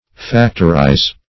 factorize - definition of factorize - synonyms, pronunciation, spelling from Free Dictionary
factorize \fac"tor*ize\, v. t. [imp. & p. p. Factorized